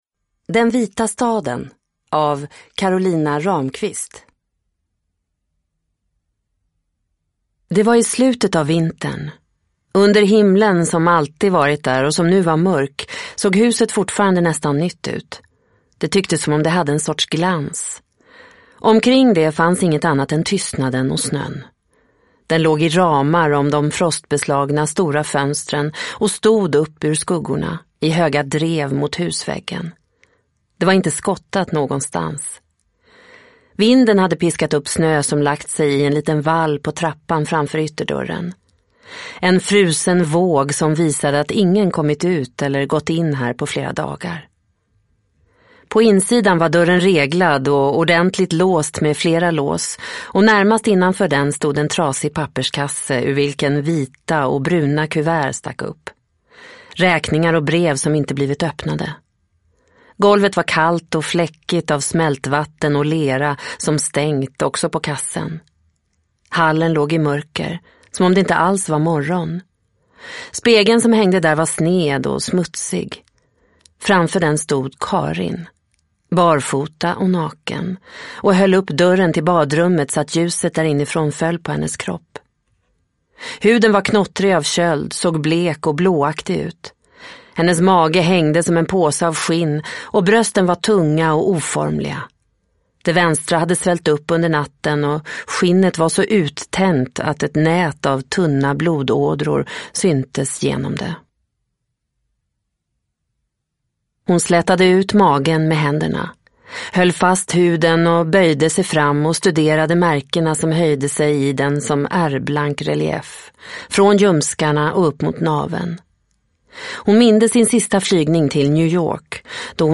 Den vita staden – Ljudbok – Laddas ner